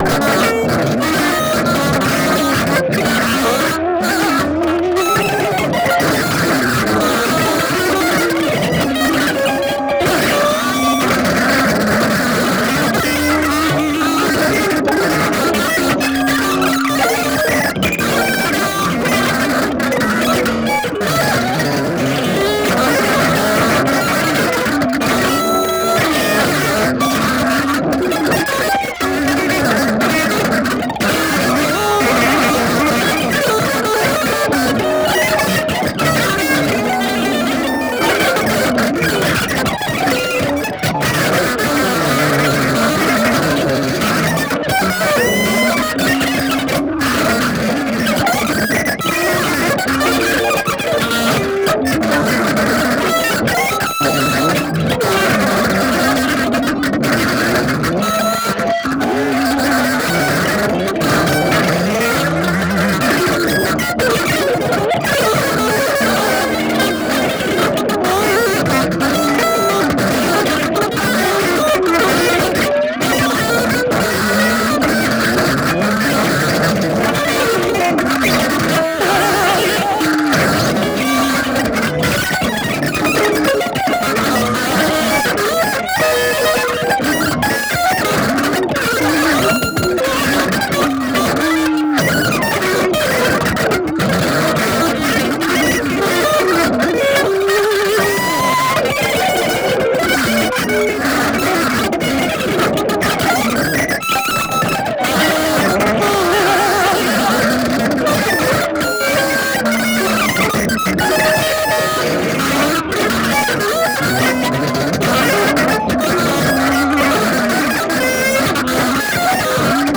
両手の10本の指が、それぞれに独立した意思を持って、能弁に語り始めたような複雑な音です。
たいへんデリケートで複雑なパンニングと、スペクトル分布を特徴とするアルバムです。
ギター愛好家の方々にはもちろん、現代音楽、先端的テクノ、実験音楽をお好きな方々にもお薦めのアルバムです。